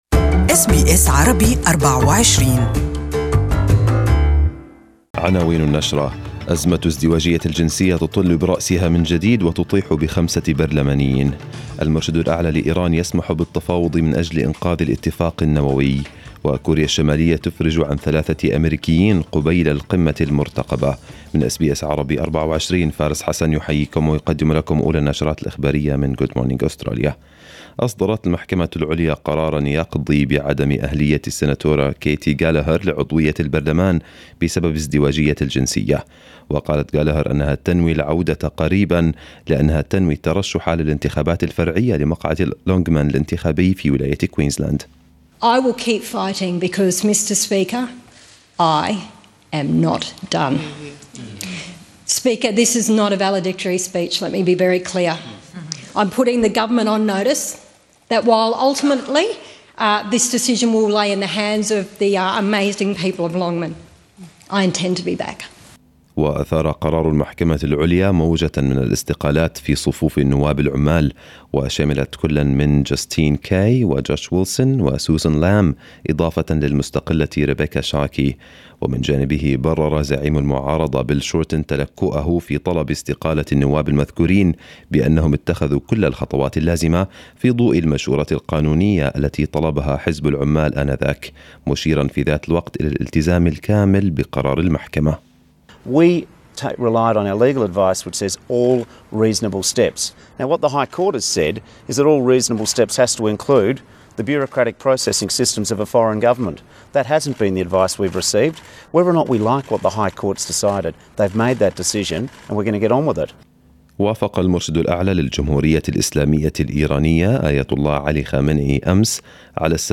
News Bulletin 10/05/2018